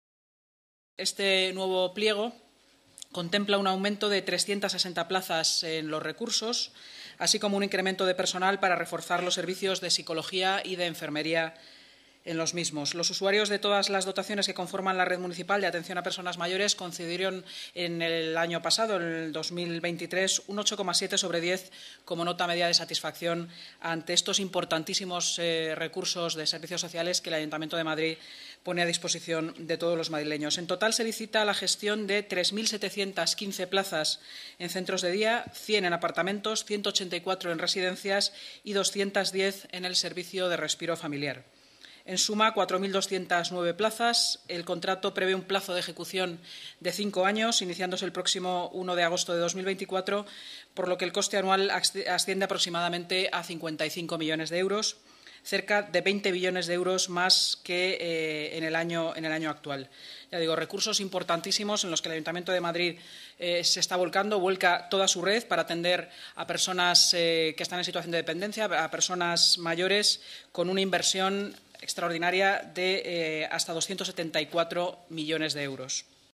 Nueva ventana:Inma Sanz, vicealcaldesa y portavoz municipal